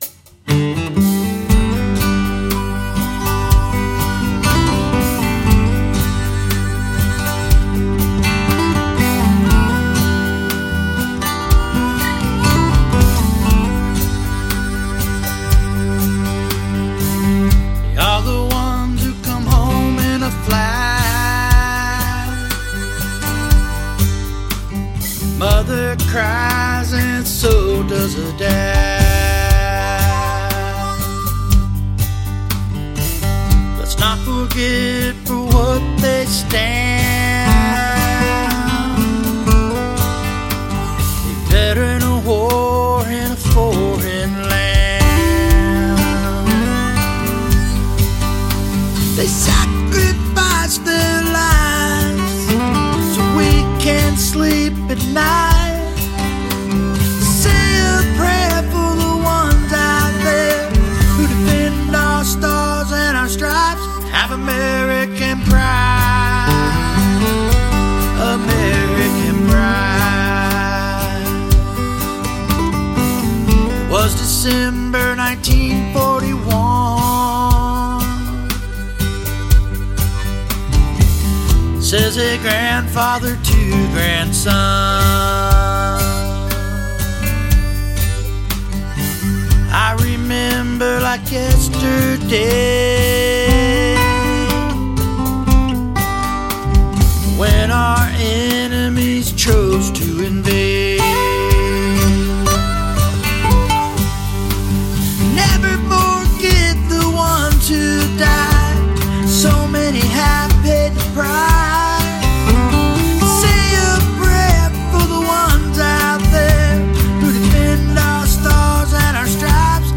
a powerful anthem